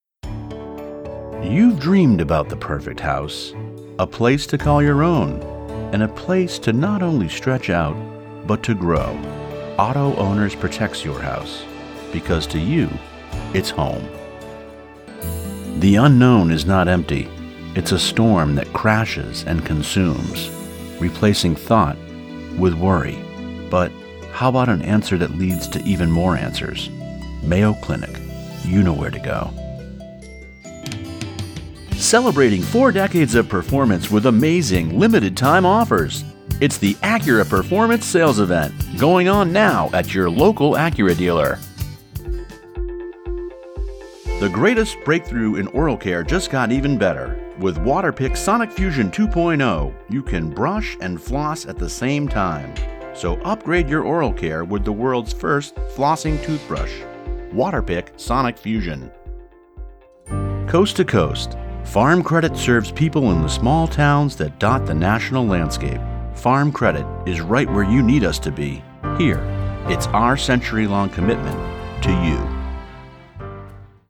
English - Midwestern U.S. English
Middle Aged
COMMERCIAL_DEMO.mp3